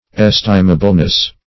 Search Result for " estimableness" : The Collaborative International Dictionary of English v.0.48: Estimableness \Es"ti*ma*ble*ness\, n. The quality of deserving esteem or regard.